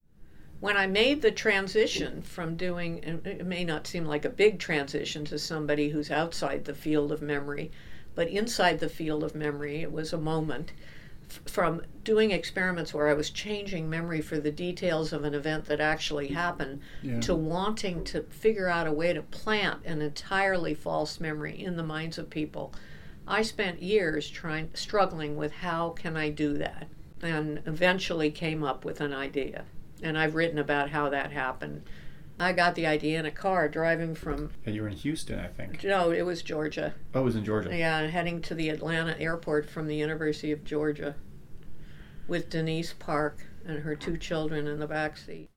Dr. Loftus thought about this for years and recalls when she determined the paradigm that might allow this to be tested directly: